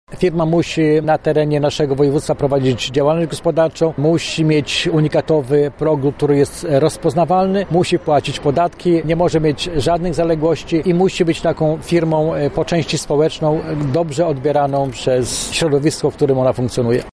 Marka Lubelskie 2 – dodaje Grabczuk.